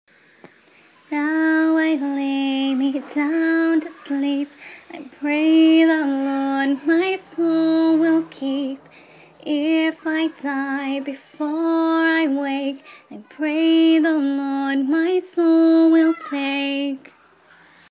So here’s a couple of prayers in tune form that might just be a hit in your house!
Melody: See-saw, Margery Daw